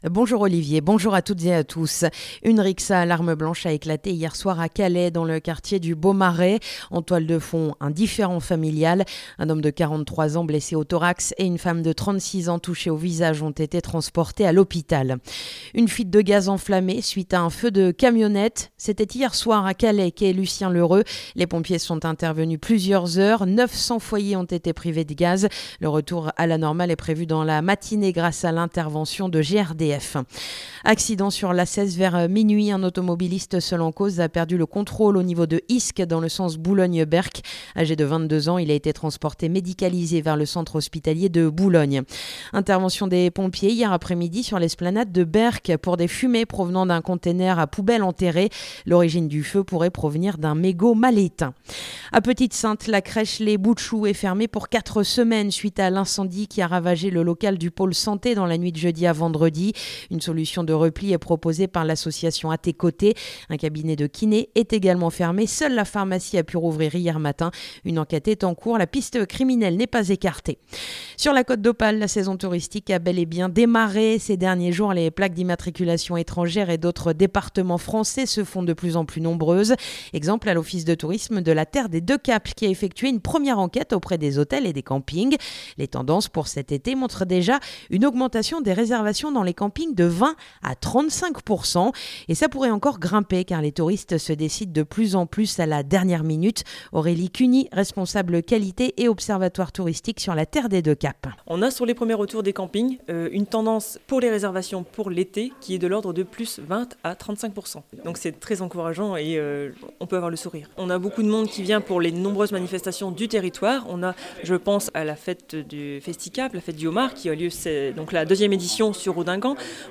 Le journal du samedi 3 mai